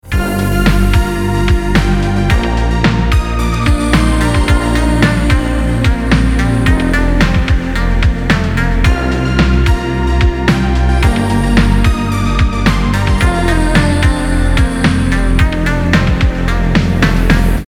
• Качество: 320, Stereo
инструментальные
synthwave
Retrowave
синти-поп